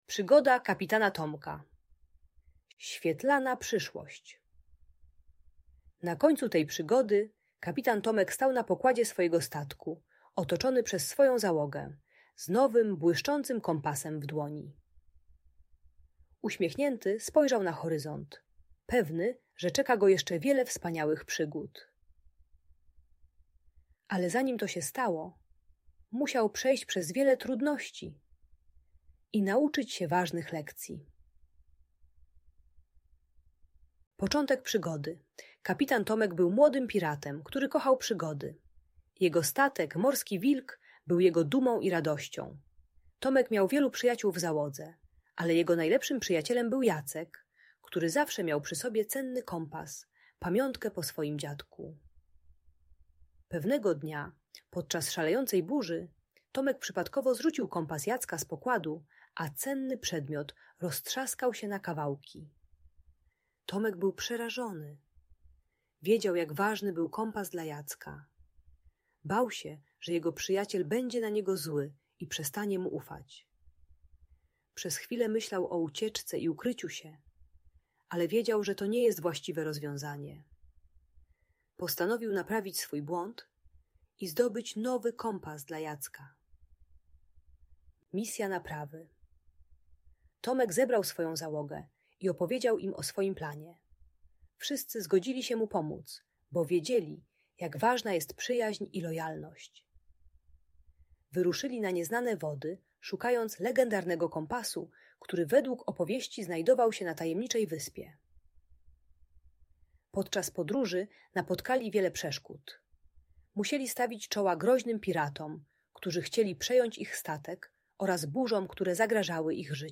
Przygoda Kapitana Tomka - historia o przyjaźni i odwadze - Audiobajka dla dzieci